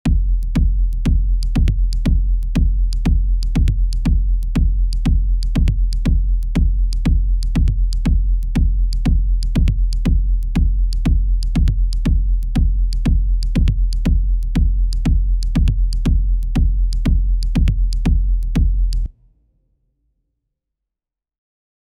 Deep sound, bass line, chill echoe, electronic rhitmic percussion with etno motives
deep-sound-bass-line-chil-7fpjleo4.wav